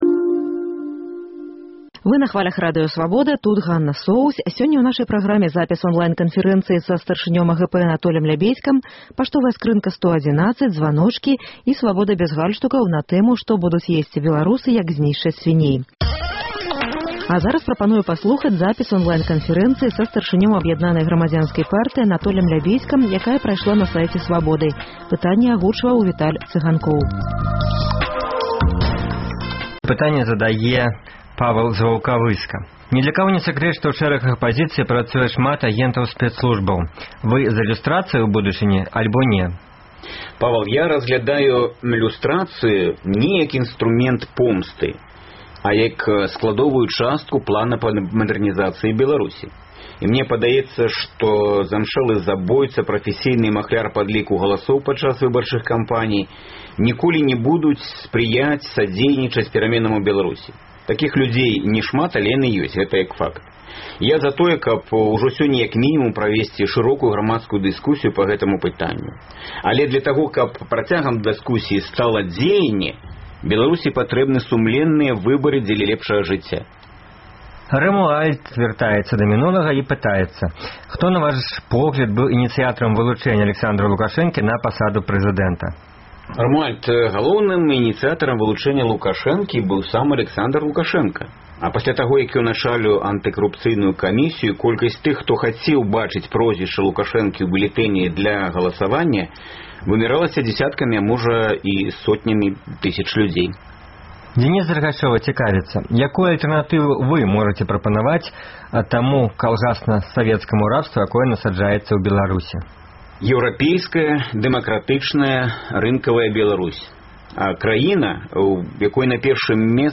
Запіс онлайн-канфэрэнцыі з лідэрам Аб’яднанай грамадзянскай партыі Анатолем Лябедзькам.